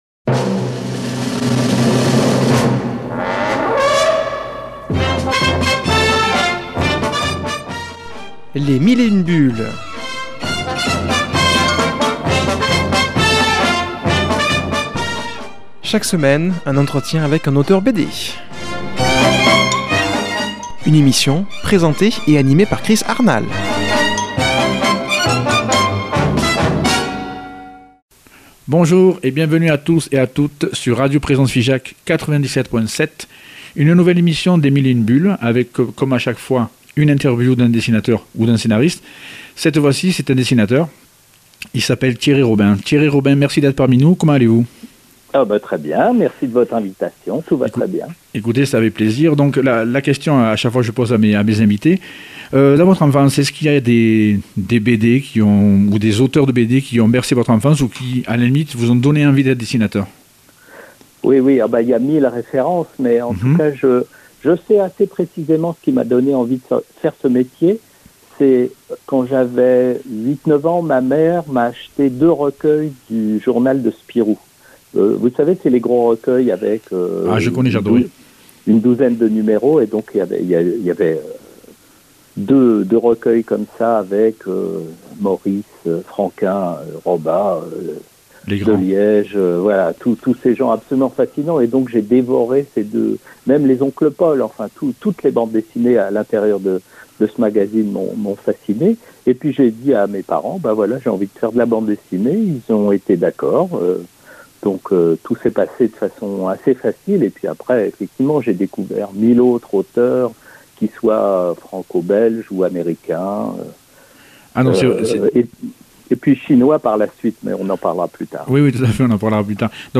invitée au téléphone